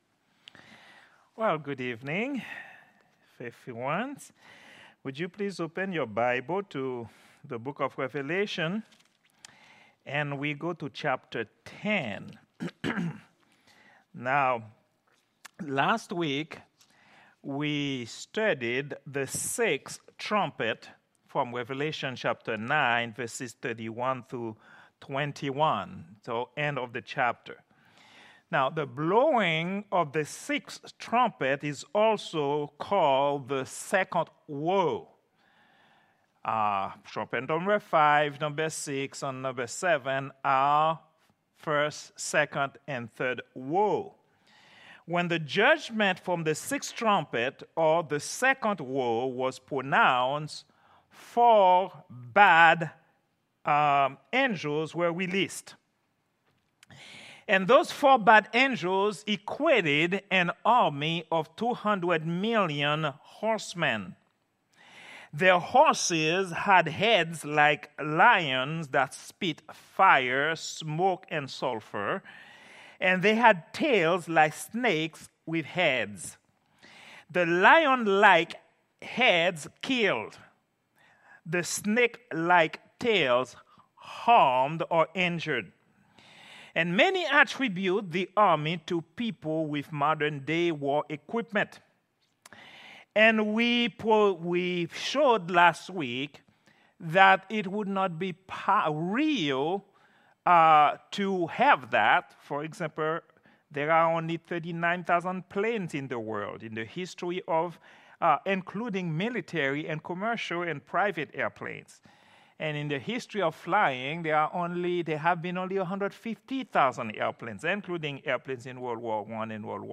Prayer_Meeting_09_25_2024.mp3